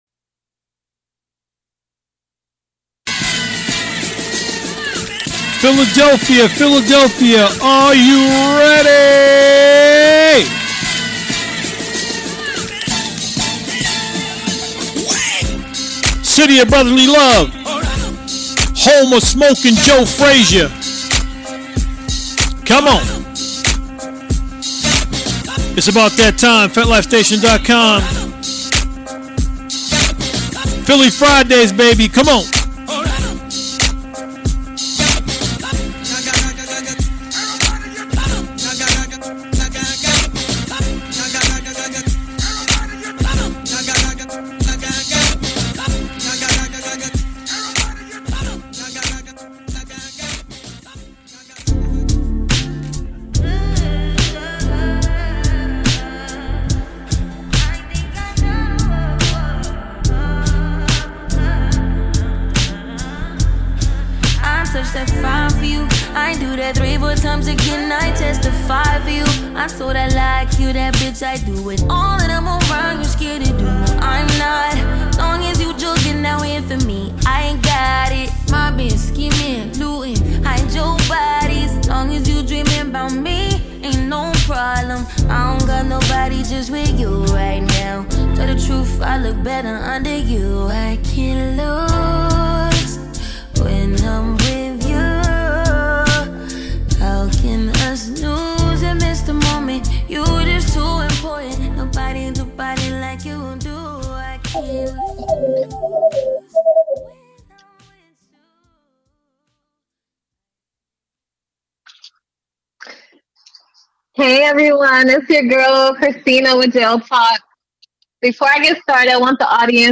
interview people who have builded love relationships with people in prison.